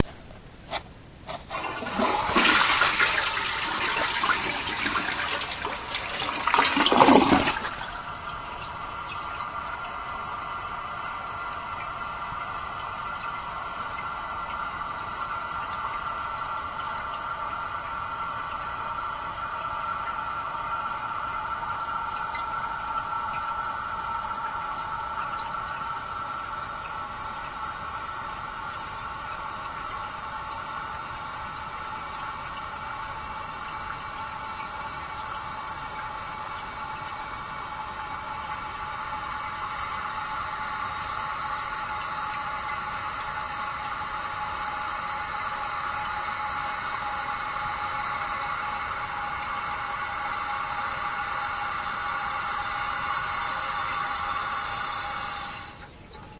卫生间的水 " 马桶水箱盖
描述：移动马桶的水箱盖 用Tascam DR07 MKII录制 我已经使用过freesound.org了，是时候回馈了！
标签： 厕所 小便 厕所 粪便 浴室 厕所 洗手间
声道立体声